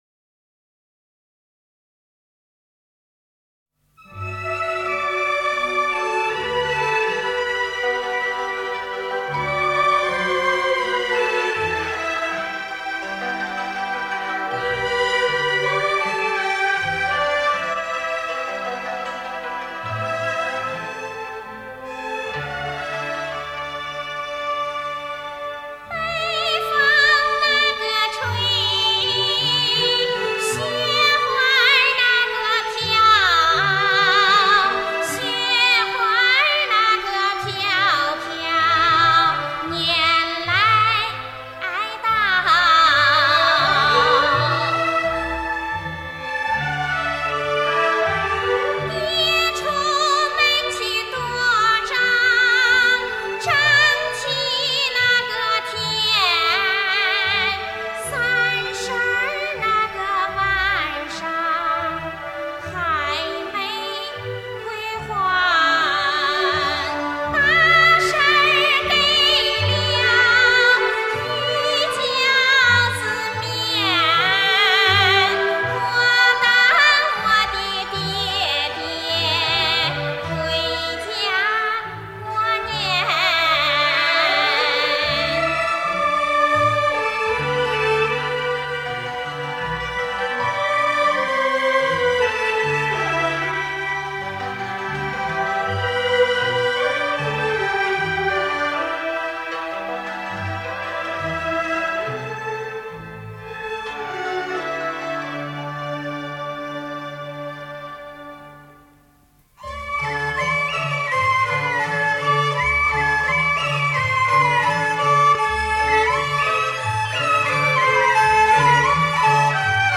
独一无二的魅力唱腔，蕴味浓郁的地方民族风情。